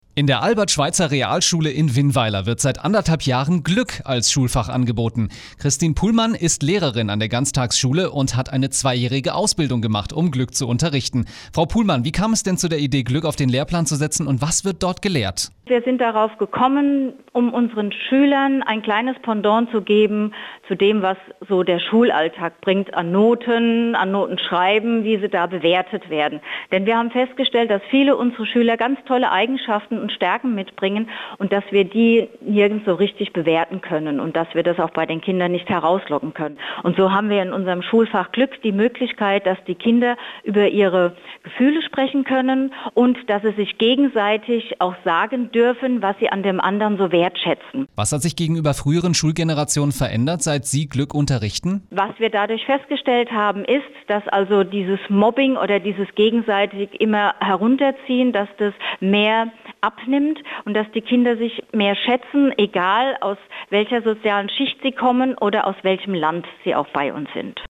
Telefon-Interview